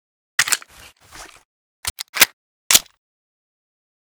grach_reload_empty.ogg